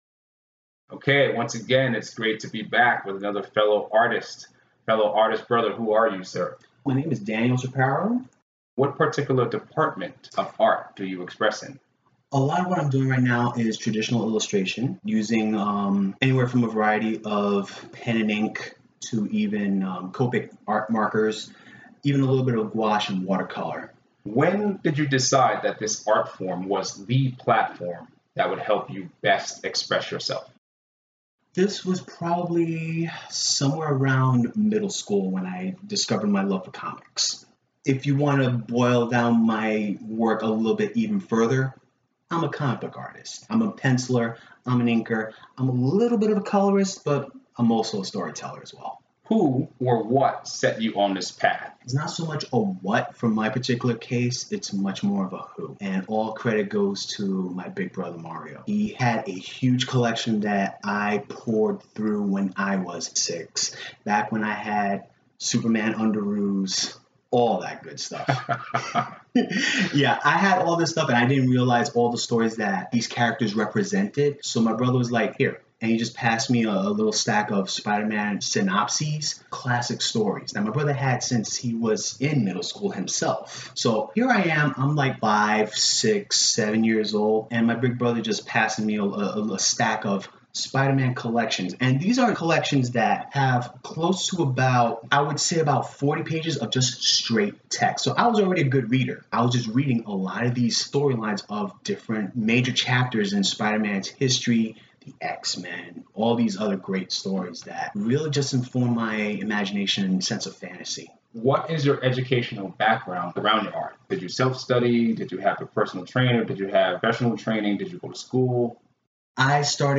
His thoughts flow with a spontaneous, blue-collar regality that informs a well-earned experience.